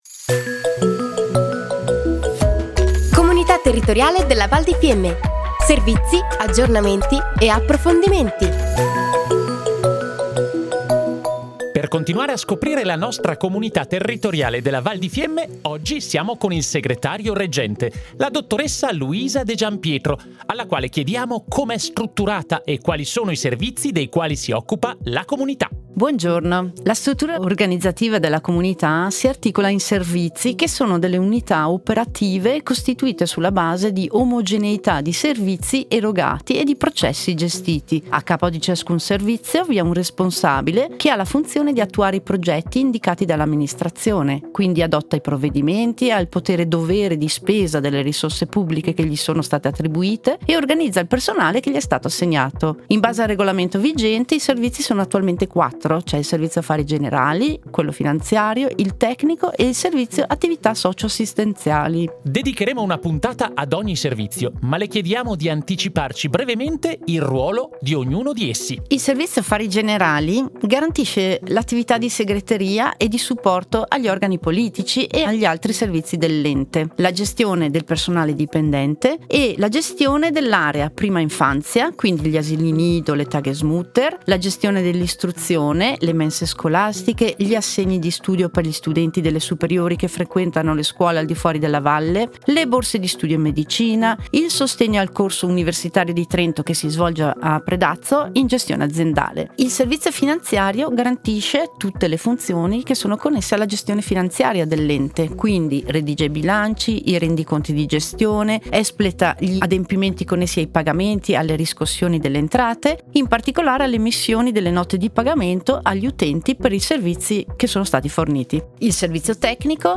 Interviste / La Comunità si presenta tramite Radio Fiemme